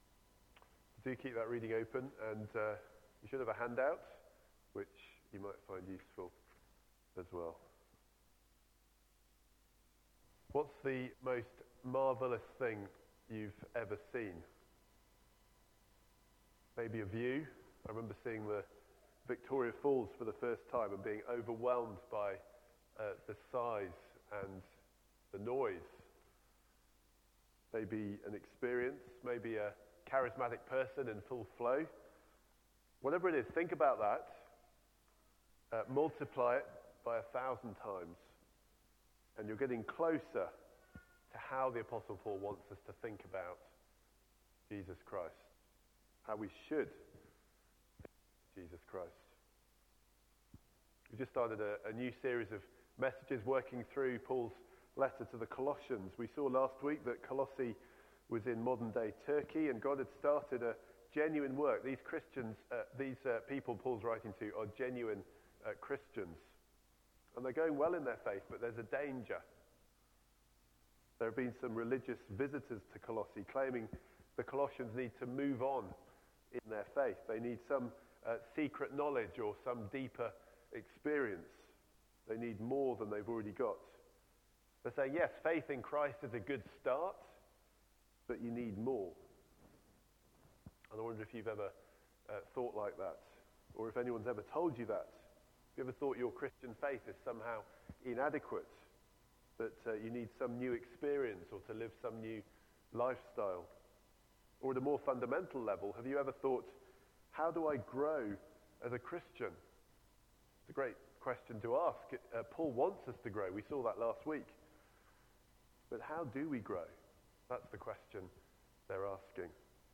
Media Library The Sunday Sermons are generally recorded each week at St Mark's Community Church.
Theme: Christ is all we need Sermon